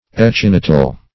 Echinital \E*chin"i*tal\, a.
echinital.mp3